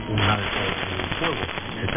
描述：西班牙语新闻阅读器被无线电噪音/静电所压倒，而且这些词语是不可忽视的。
Tag: 声音 西班牙语 收音机 噪音 特温特 大学 AM uninteligible